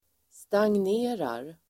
Uttal: [stangn'e:rar]
stagnerar.mp3